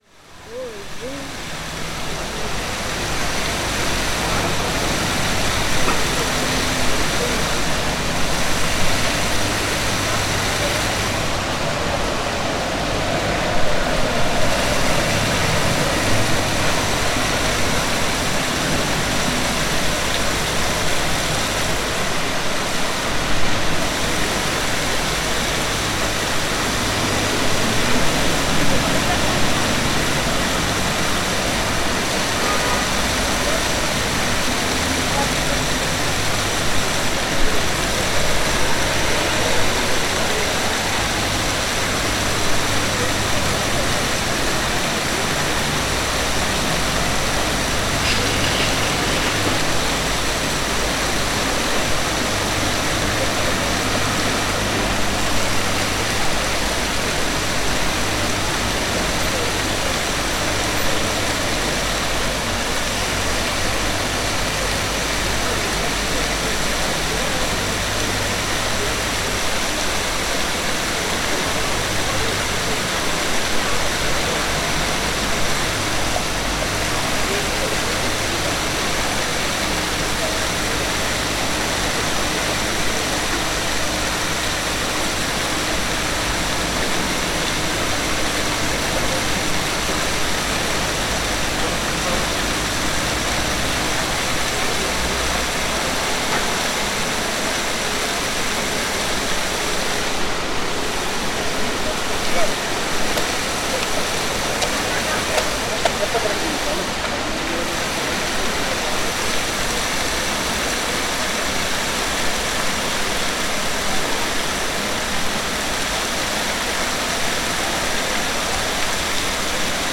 Gravação do ruído da água do fontanário. Gravado com Fostex FR-2LE e um microfone Tellinga.
Tipo de Prática: Paisagem Sonora Rural
Viseu-Praça-do-Rossio-Fontanário.mp3